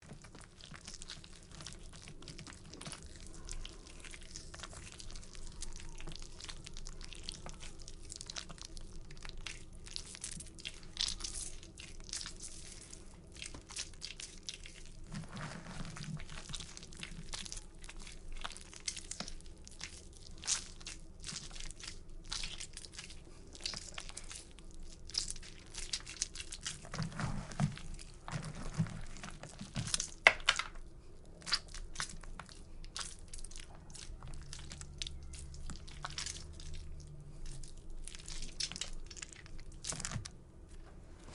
Pumpkin Guts Digging and Squishing
Pumpkin Guts Digging and Squishing is a free ui/ux sound effect available for download in MP3 format.
yt_etWB44djj3U_pumpkin_guts_digging_and_squishing.mp3